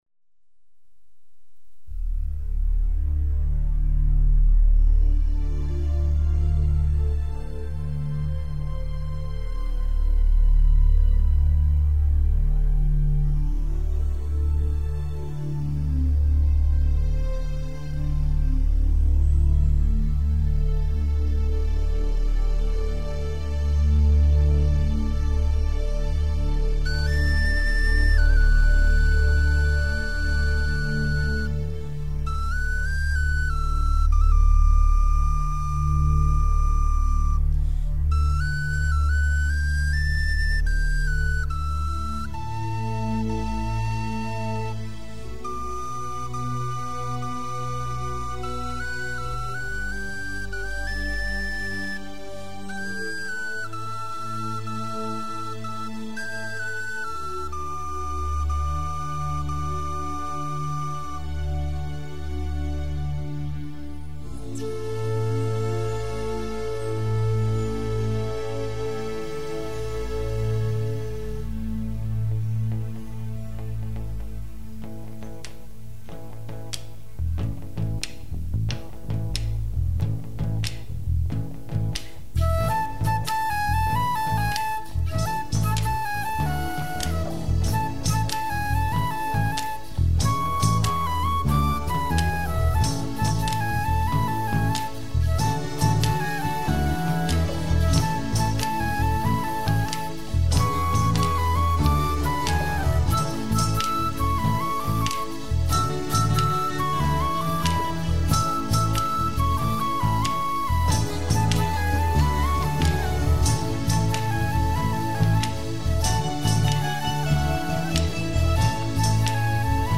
alte irische Song
nach einem ruhigen Anfang eine starke rhythmische Aufwertung